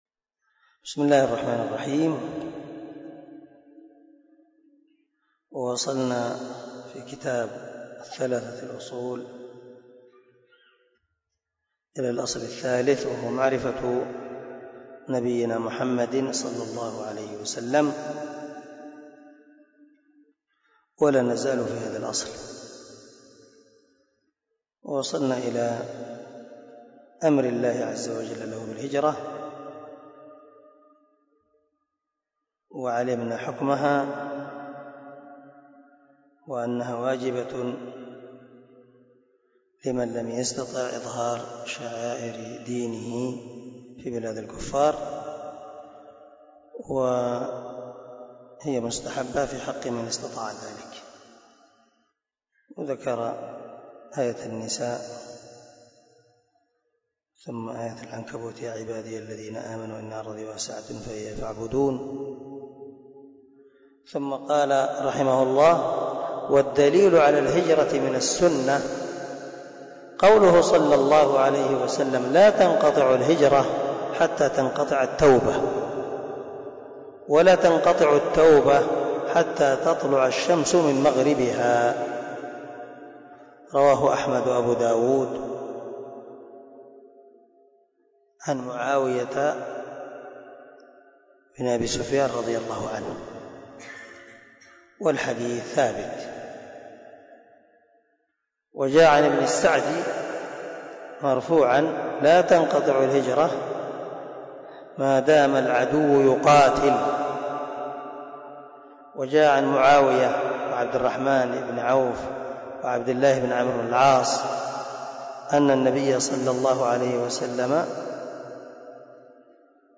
🔊 الدرس 36 من شرح الأصول الثلاثة